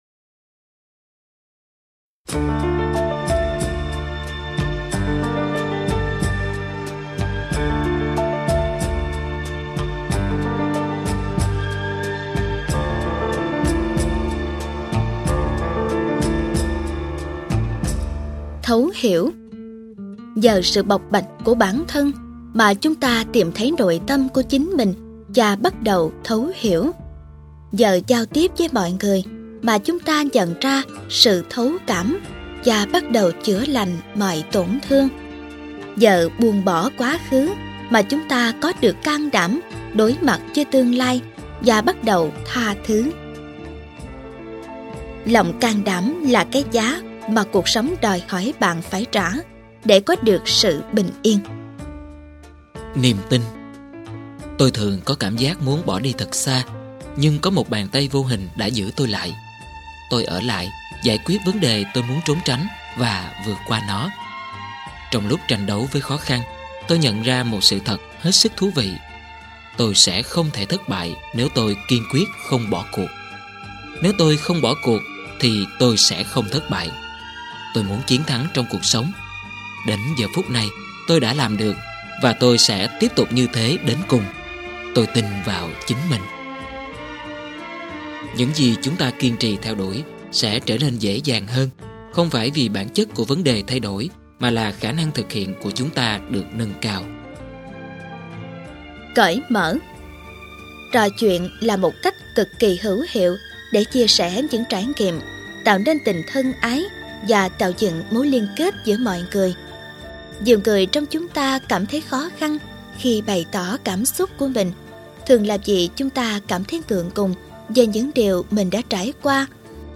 Sách nói Chicken Soup 21 - Cảm Hứng Cuộc Sống - Jack Canfield - Sách Nói Online Hay